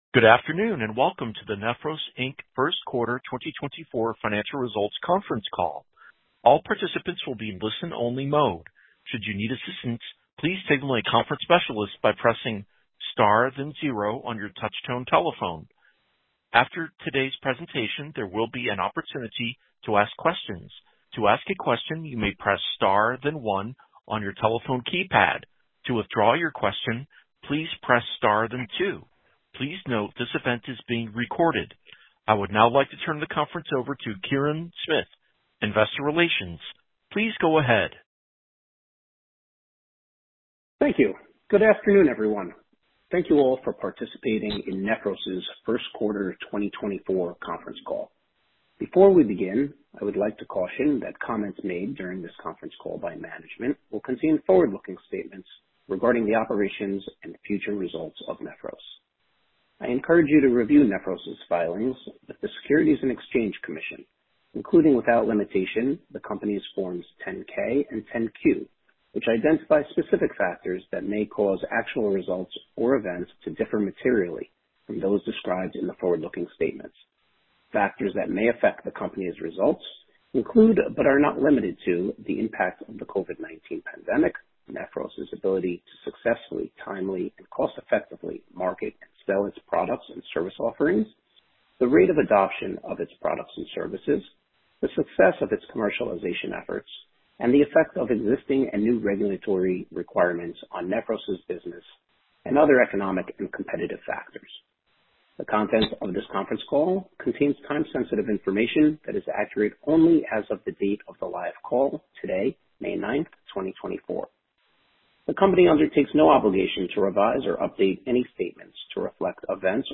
Listen to the replay of the Q1 2024 conference call